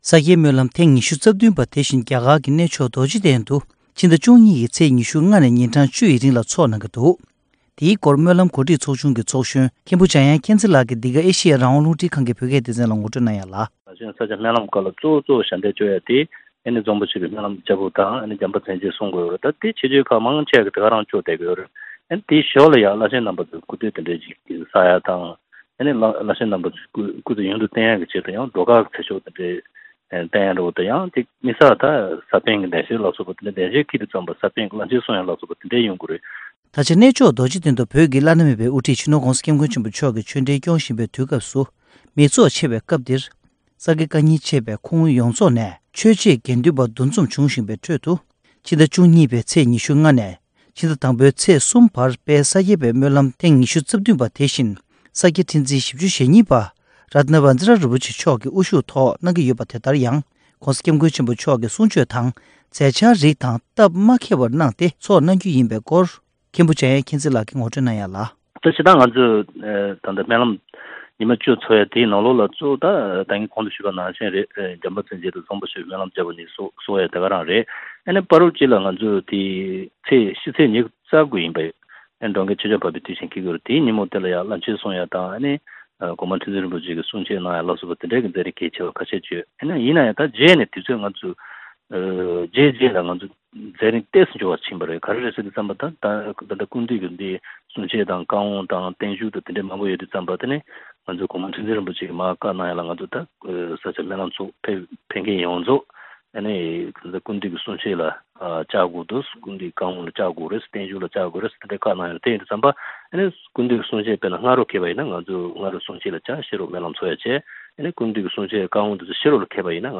སྒྲ་ལྡན་གསར་འགྱུར། སྒྲ་ཕབ་ལེན།
གནད་དོན་གླེང་མོལ་གྱི་ལས་རིམ་ནང་།